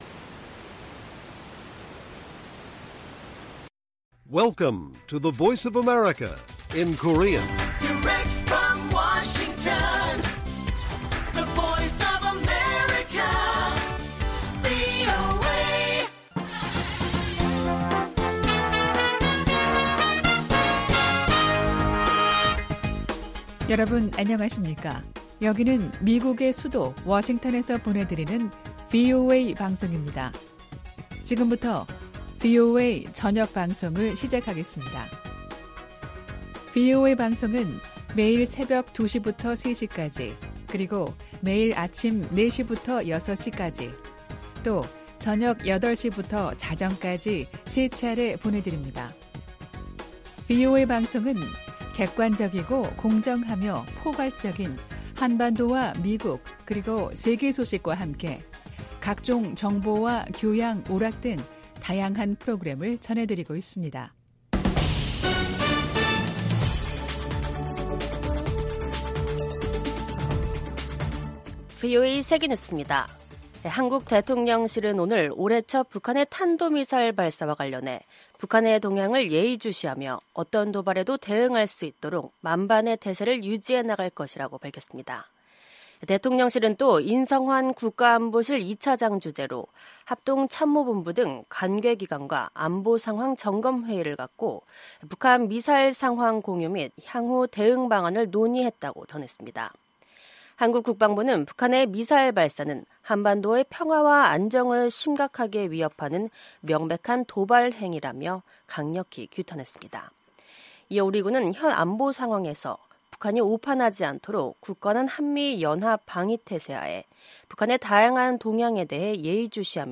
VOA 한국어 간판 뉴스 프로그램 '뉴스 투데이', 2025년 1월 6일 1부 방송입니다. 한국의 비상계엄 사태 이후 토니 블링컨 미국 국무장관이 한국을 처음으로 방문했습니다. 한국을 방문한 블링컨 장관과 조태열 외교장관이 서울 외교부 청사에서 미한 외교장관 회담을 연 오늘 북한은 극초음속 미사일로 추정되는 탄도미사일을 동해상에 발사했습니다.